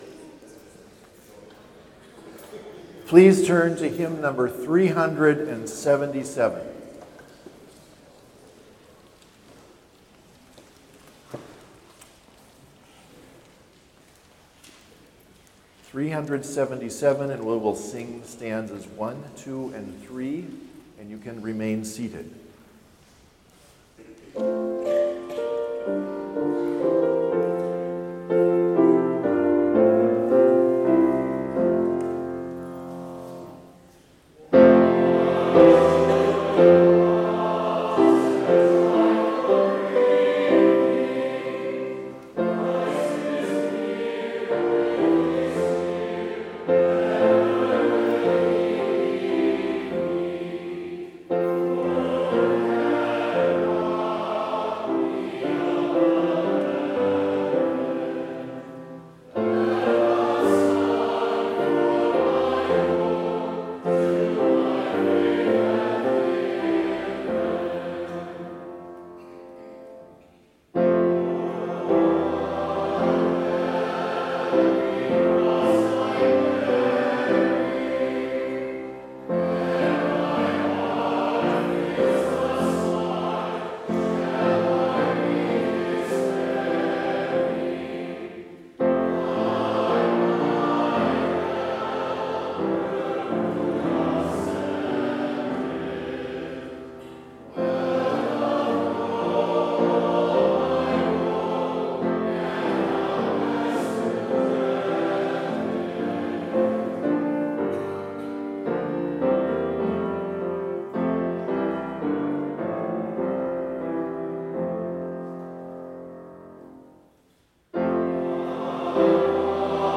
Complete service audio for Chapel - May 10, 2022